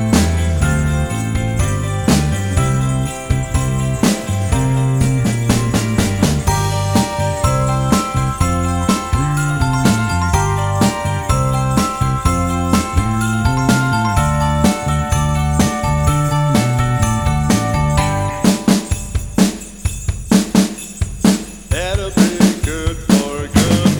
Intro Cut And No Sax Solo Rock 3:44 Buy £1.50